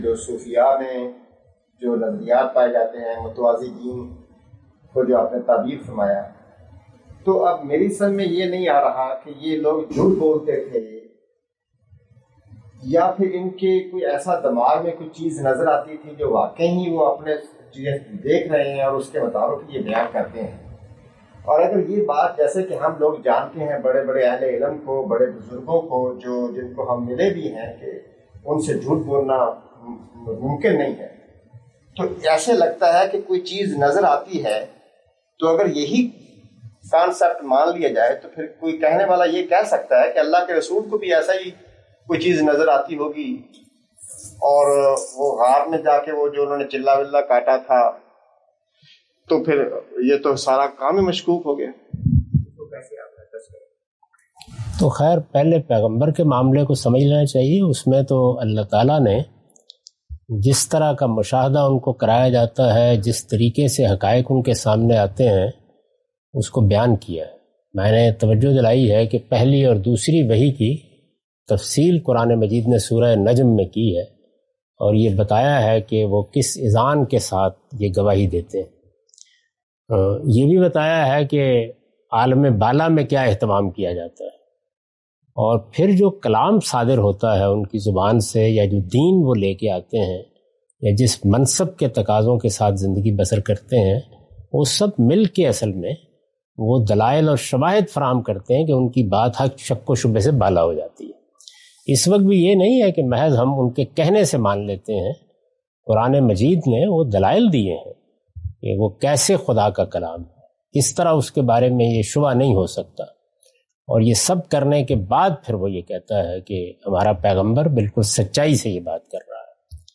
Experiences and Observations of the Sufis: Questions and Answers with Mr. Javed Ahmed Ghamidi (29 August 2024)